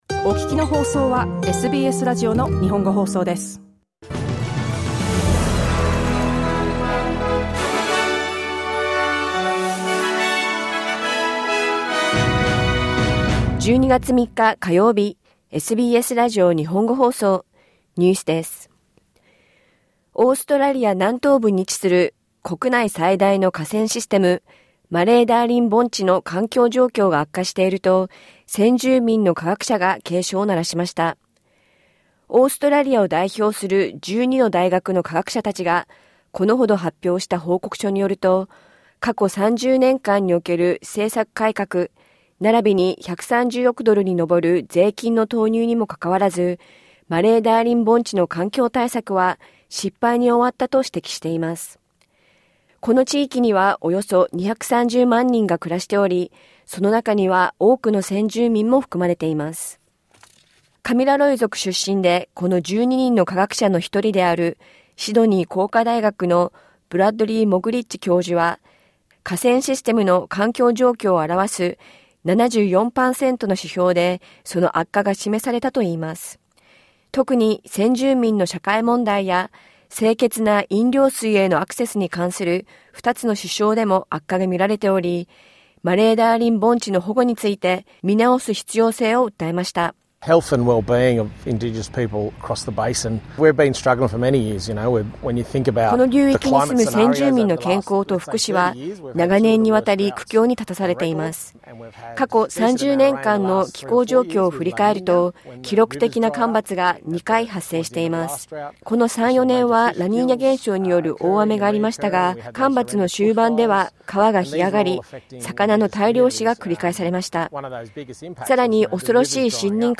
オーストラリア最大の河川システムの環境状況が悪化しているとする新たな報告書に、先住民の科学者が警鐘を鳴らしています。クイーンズランド州の新たな青少年犯罪法について、国連が、「子供の権利を無視している」と非難しました。 午後１時から放送されたラジオ番組のニュース部分をお届けします。